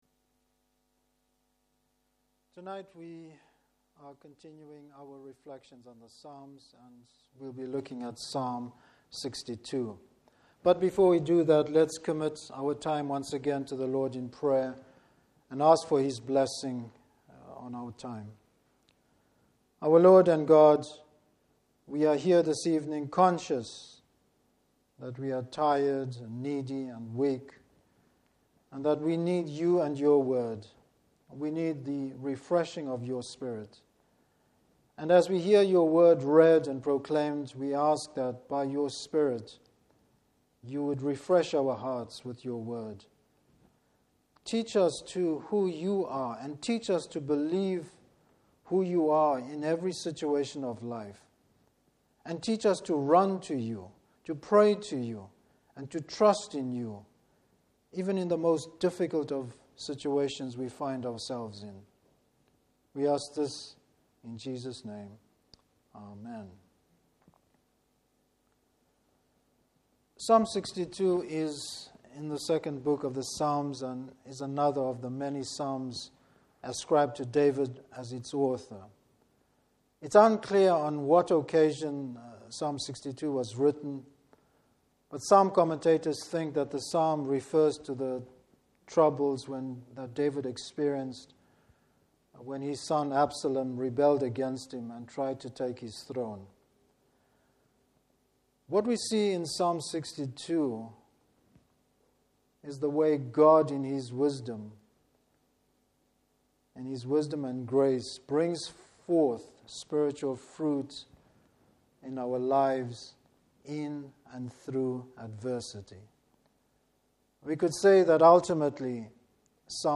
Service Type: Evening Service How it is important for the Christian to get the right perspective.